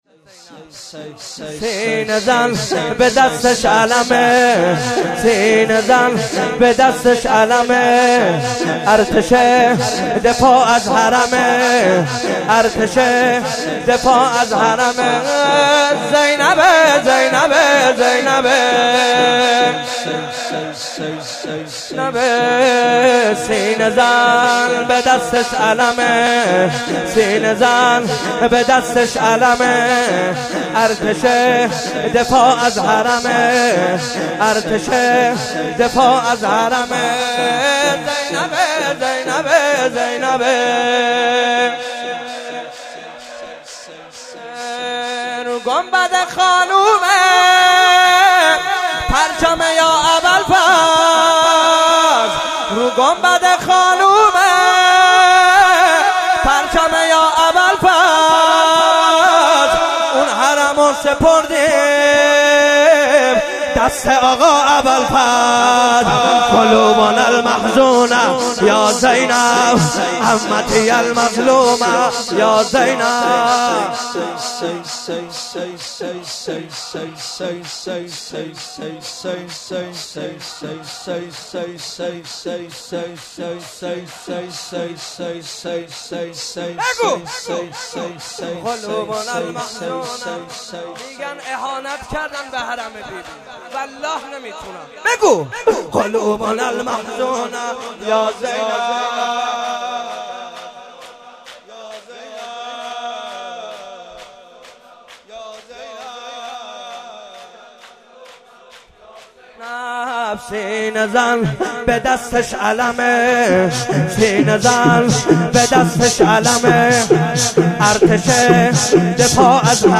04.sineh zani.mp3